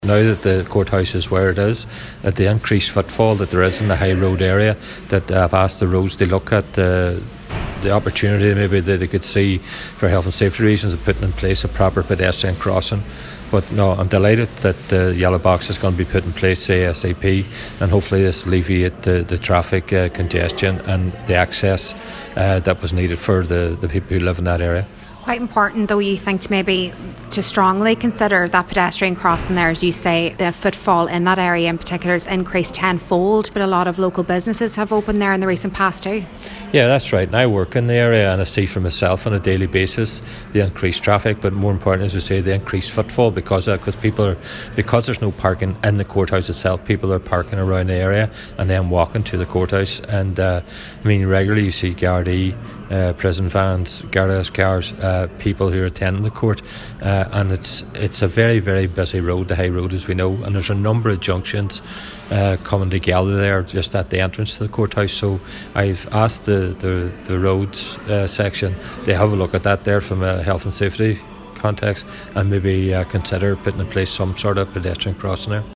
Cllr. Gerry McMonagle says with such a huge increase in footfall on a road which also features a lot of junctions, it’s important the local authority consider making it safer for pedestrians: